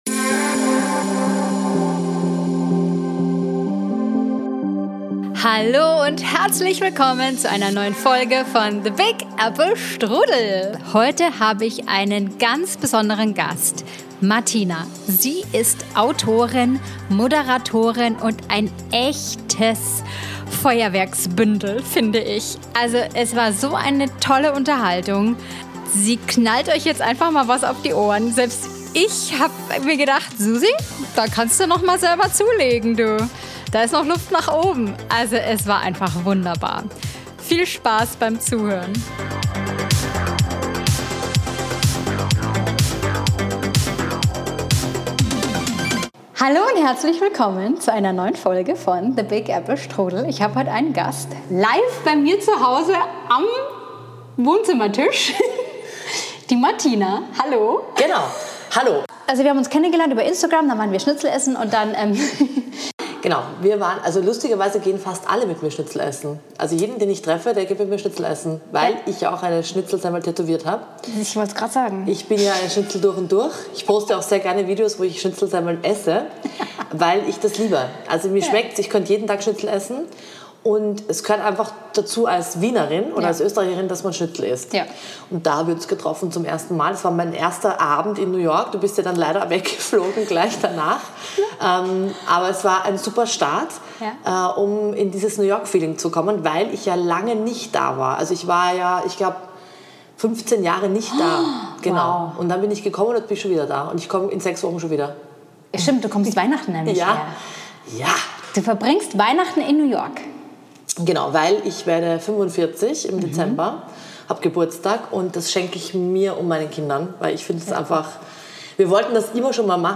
Lass Dich von diesem Gespräch inspirieren und lachen ist garantiert.